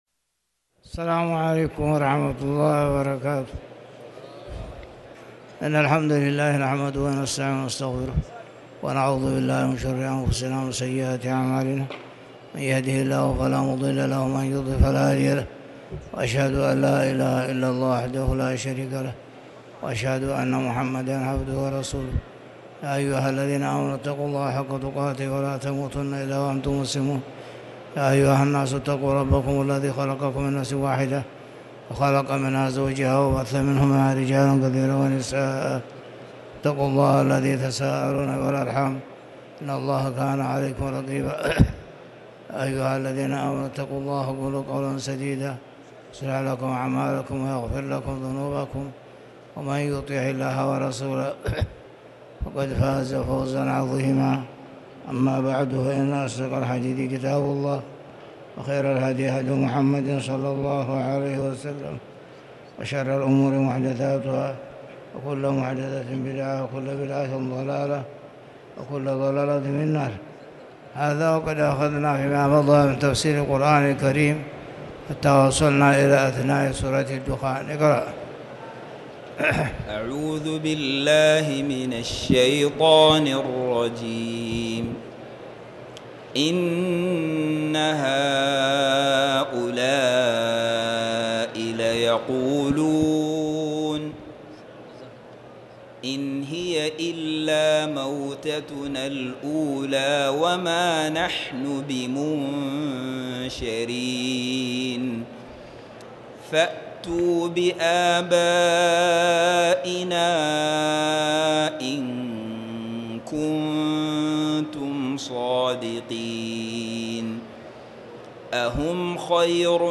تاريخ النشر ٢٤ محرم ١٤٤٠ هـ المكان: المسجد الحرام الشيخ